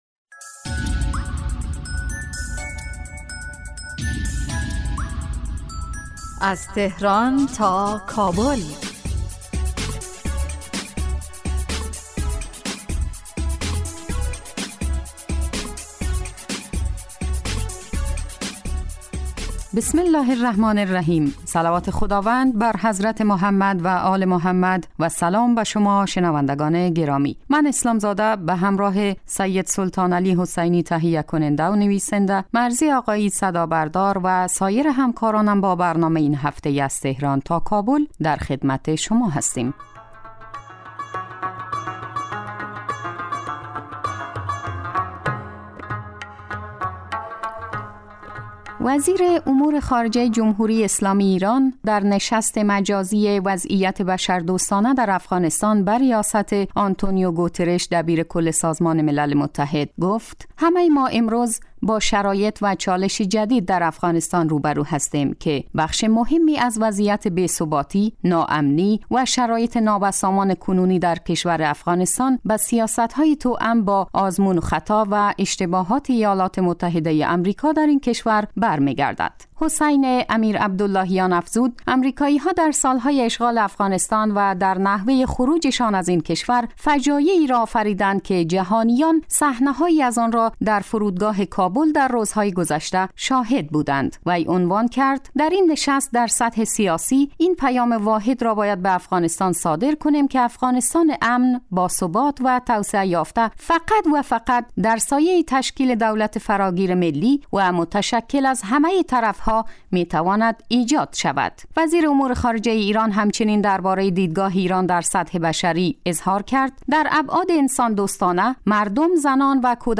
اعلام آمادگی ایران برای تسهیل در ارسال کمک های انسان دوستانه کشورهای مختلف به افغانستان، تاکید ایران بر حل مسایل افغانستان از طریق گفت و گو و حمایت از خواست مردم این کشور. برنامه از تهران تا کابل به مدت 15 دقیقه روز جمعه در ساعت 18:40 پخش می شود. این برنامه به رویدادهای سیاسی، فرهنگی، اقتصادی و اجتماعی مشترک ایران و افغانستان می پردازد.